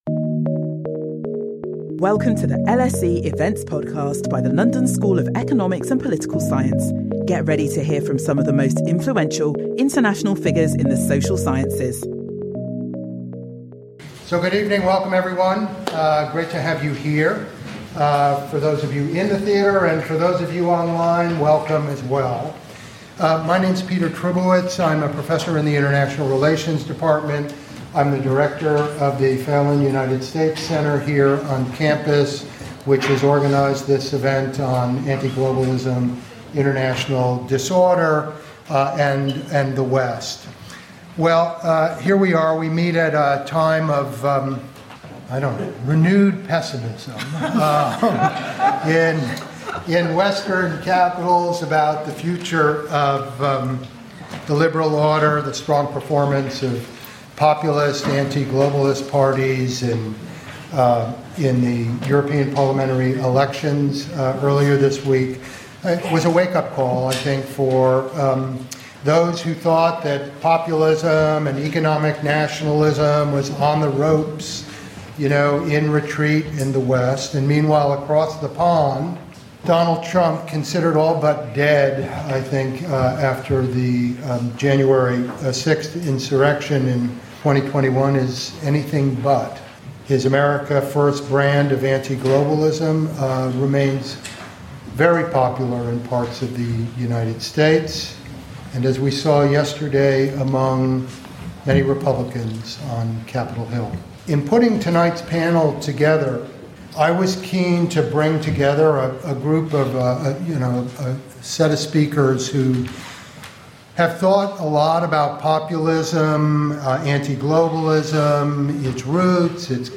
Our panel of experts consider the international implications of populism’s continuing success in Western democracies.